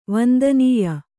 ♪ vandanīya